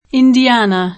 vai all'elenco alfabetico delle voci ingrandisci il carattere 100% rimpicciolisci il carattere stampa invia tramite posta elettronica codividi su Facebook Indiana [ ind L# na ; ingl. indi-ä ^ në o indi- # anë ] top. f. (S. U.)